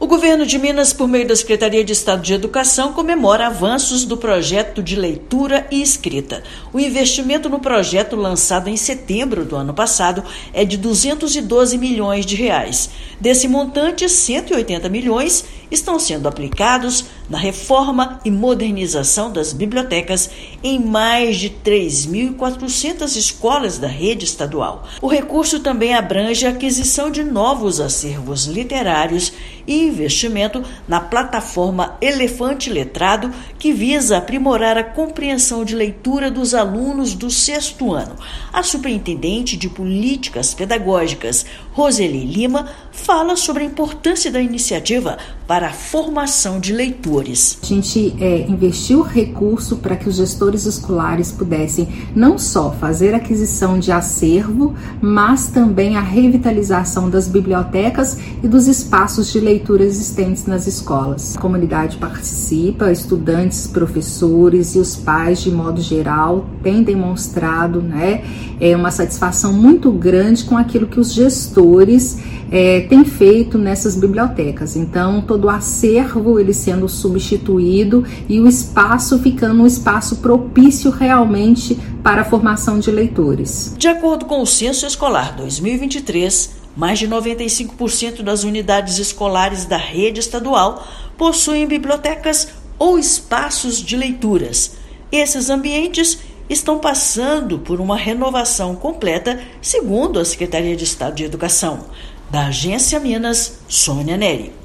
Com investimento recorde de R$ 212 milhões, Projeto de Leitura e Escrita transforma bibliotecas estaduais em ambientes modernos e acolhedores. Ouça matéria de rádio.